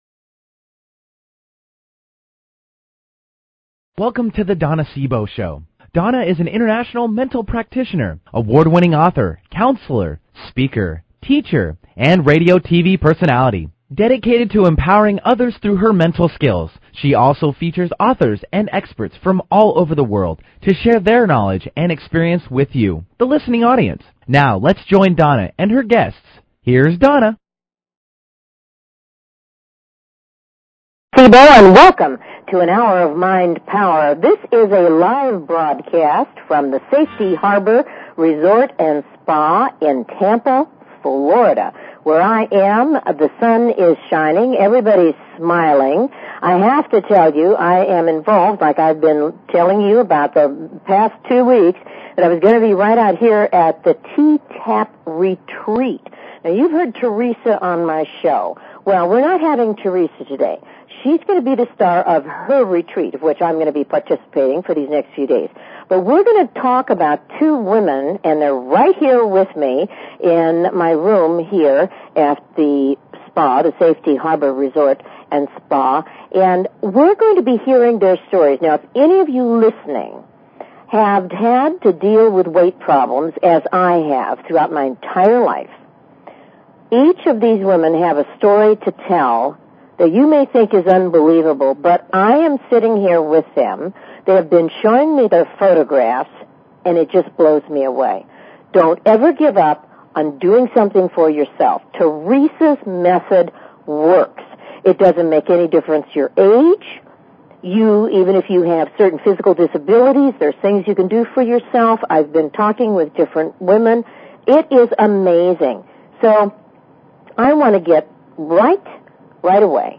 Talk Show Episode
This is a special remote broadcast, live, from Tampa, Florida.